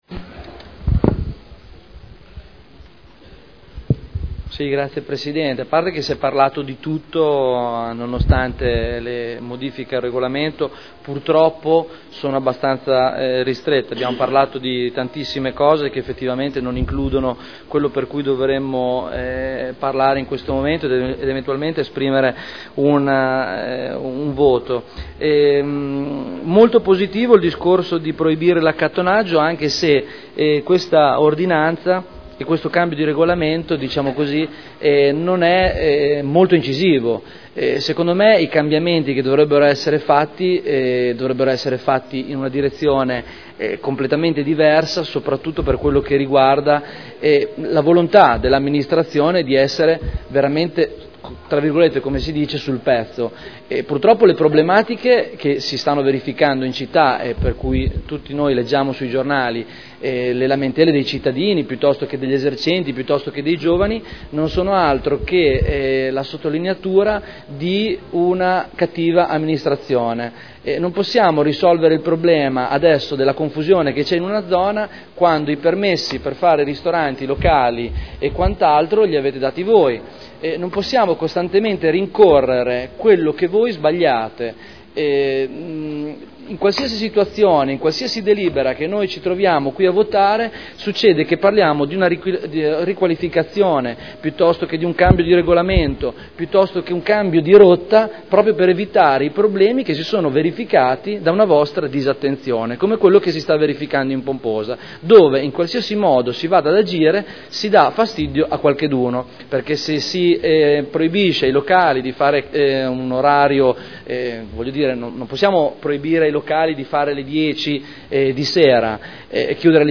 Stefano Barberini — Sito Audio Consiglio Comunale